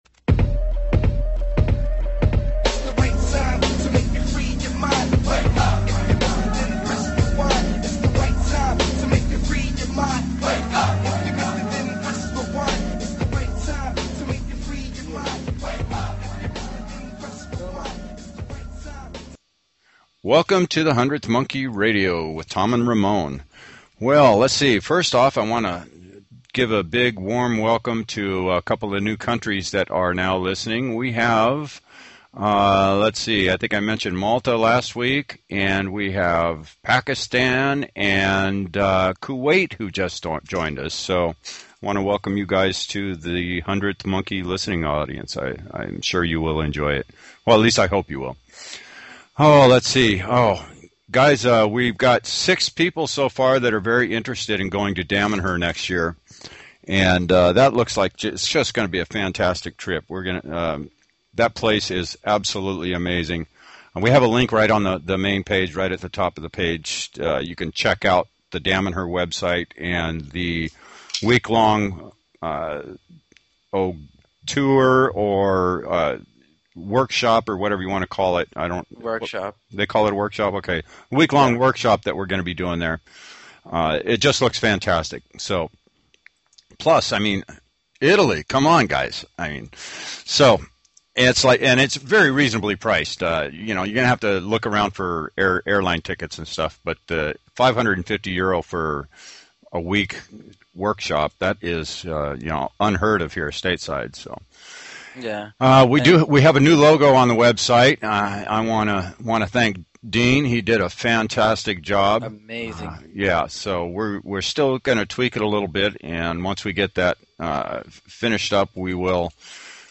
Talk Show Episode, Audio Podcast, The_Hundredth_Monkey_Radio and Courtesy of BBS Radio on , show guests , about , categorized as